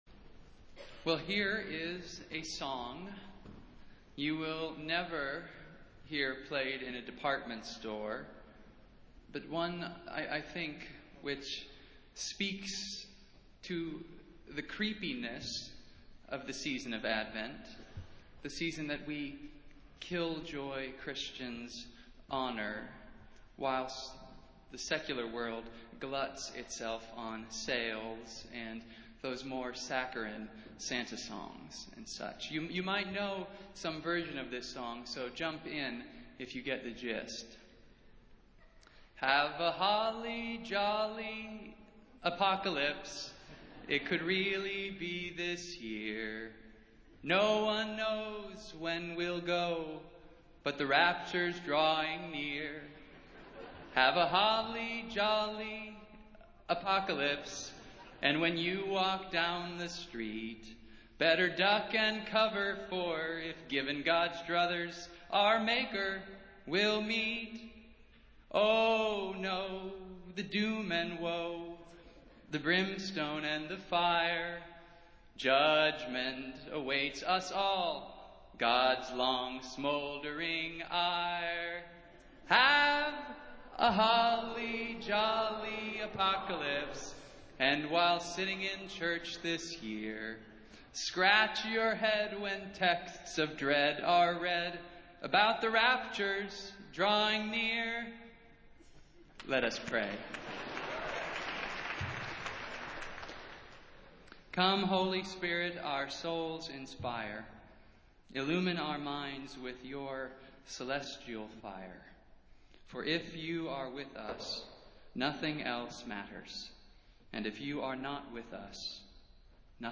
Festival Worship - First Sunday in Advent